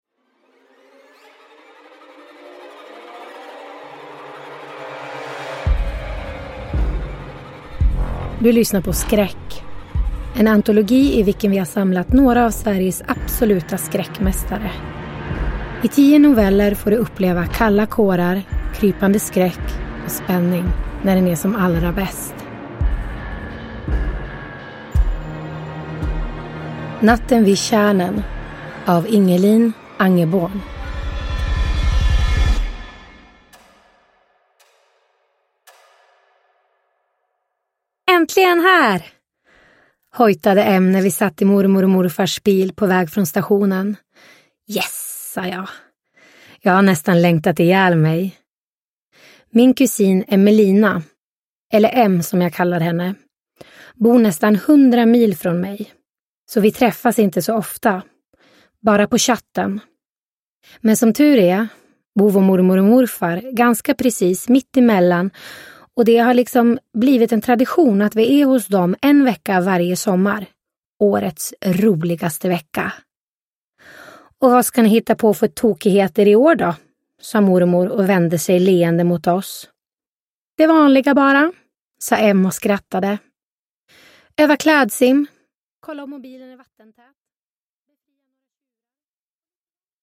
Skräck - Natten vid tjärnen – Ljudbok – Laddas ner